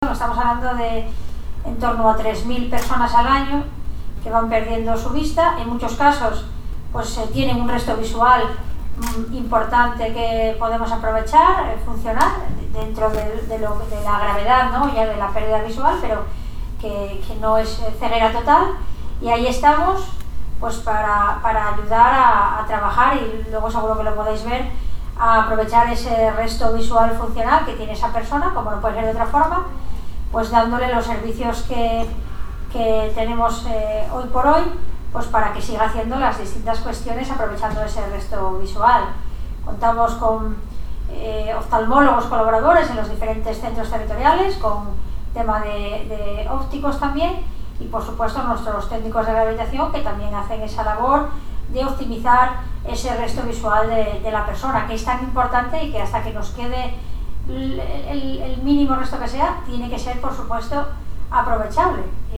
durante una jornada celebrada en la sede de la Delegación Territorial de la ONCE en Madrid